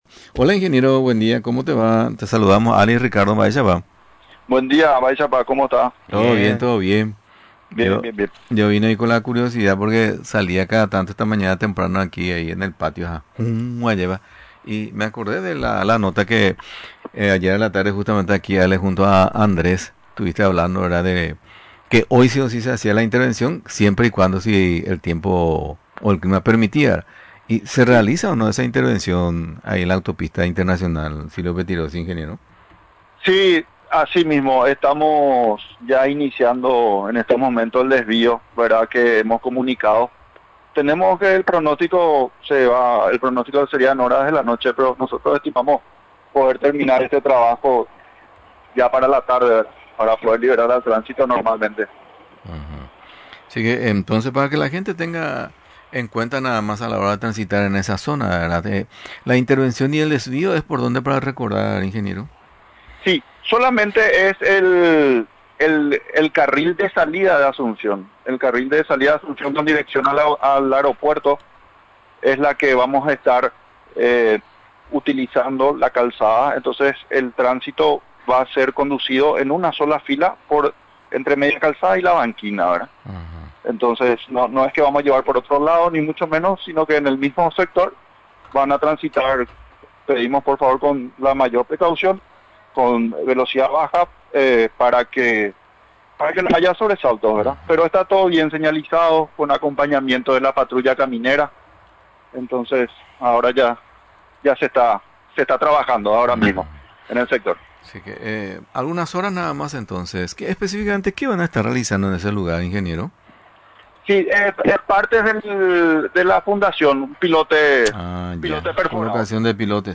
Durante la entrevista en Radio Nacional del Paraguay, mencionó las tareas técnicas que desarrollarán durante el tiempo indicado. Además de referir sobre el período establecido para finalizar la citada obra de gran envergadura para Luque y distritos vecinos del departamento Central.